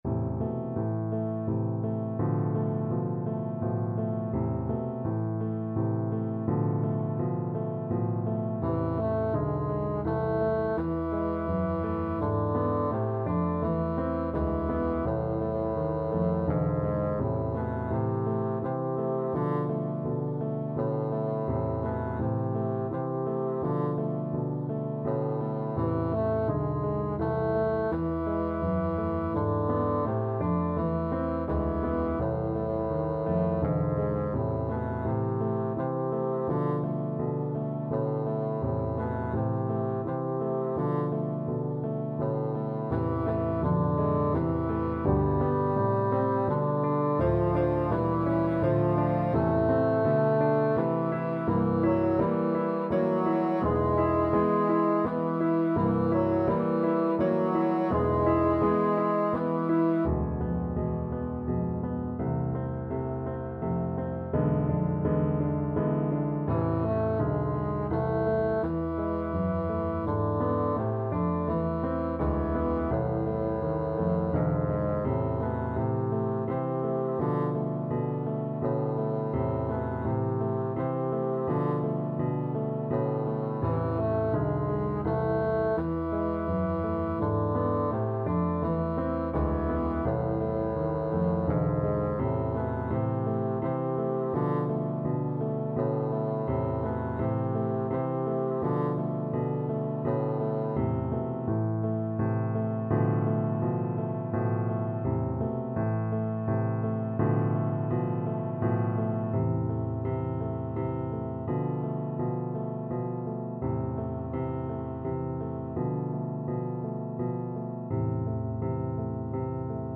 Moderato =c.84
3/4 (View more 3/4 Music)
Classical (View more Classical Bassoon Music)